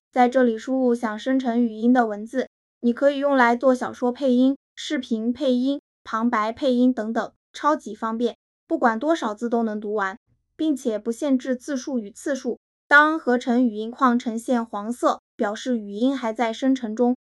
复刻后：
声音复刻采用阿里云大模型，复刻真实度非常高